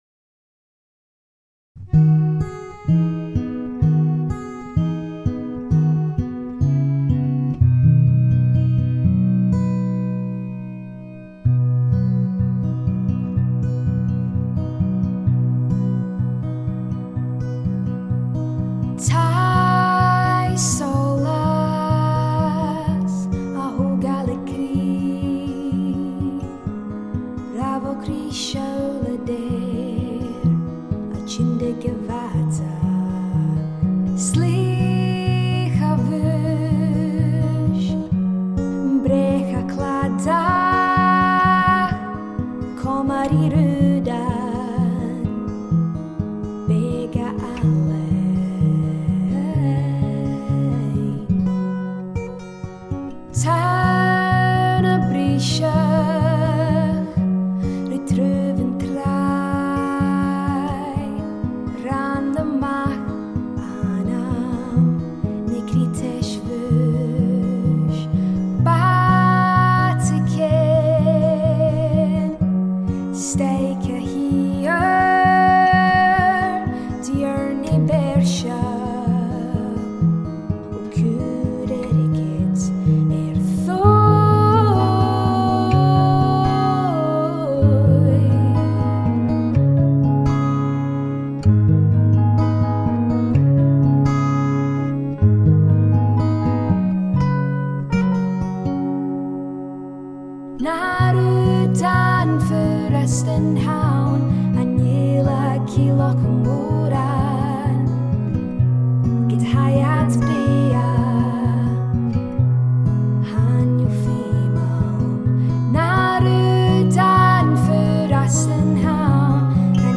I then learned how to sing it phenically therefore I cannot speak Gaelic but I love how this song has now been transformed beautifully and I feel incredibly proud to be able to sing from the language of my heritage.
singer/songwriter